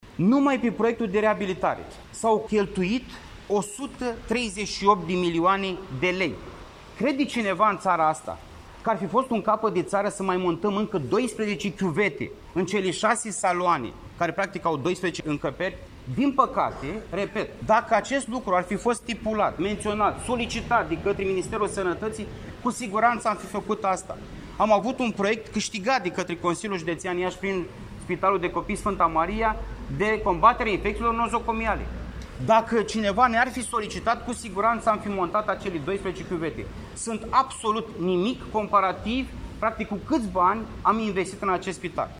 Preşedintele Consiliului Judeţean Iaşi, Costel Alexe, a declarat, astăzi, în cadrul unei conferinţe de presă susţinute la Spitalul de Copii „Sf. Maria”, că proiectul de modernizare prin eficientizare energetică a unităţii medicale a fost implementat respectând specificaţiile tehnice care au fost avizate de specialişti, inclusiv cei ai Direcţiei de Sănătate Publică Iaşi.